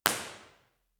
Description:  The O’Reilly Theater is a 650 seats semi-reverberant space located in the famous Pittsburg cultural district. The reverberation time is just under 1 sec, with the acoustics of the space optimized for natural support of on-stage sources.
File Type: B-Format, XY Stereo
Microphone: Core Sound Tetramic
Source: 14 sec log sweep
Test Position 3 (above)
IR_TP3_XYSTEREO_OReilly.wav